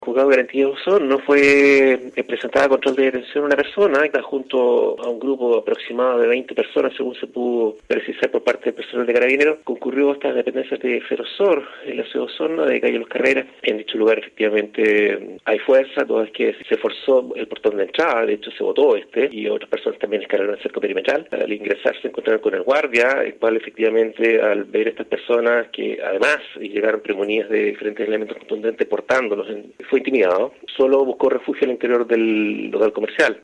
Según comentó el Fiscal Jorge Münzenmayer Cristi, en el Juzgado de Garantía de Osorno fue presentada esta persona por su participación en este violento hecho.